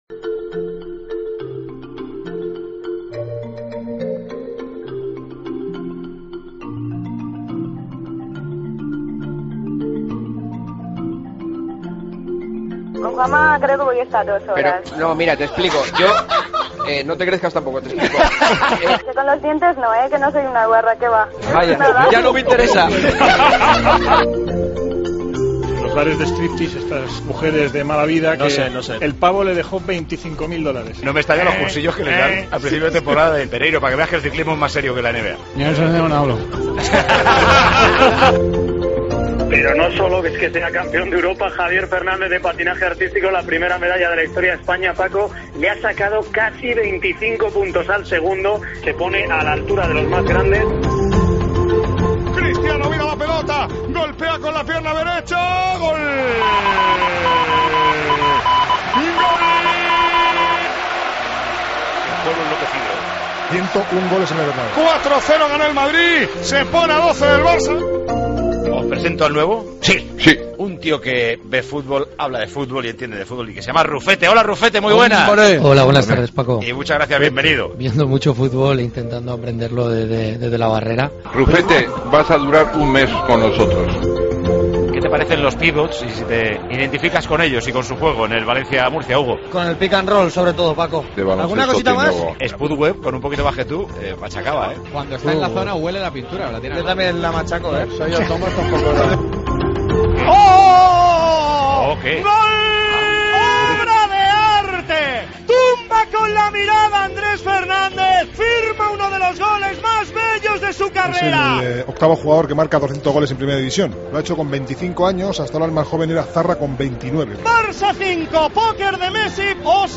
Dani Martínez imita a Luis Moya, recomendamos a Juanma Castaño depilarse las cejas, España se proclama campeona del mundo de balonmano, Messi y Cristiano golean en las victorias de Barça y Madrid...
Con Paco González, Manolo Lama y Juanma Castaño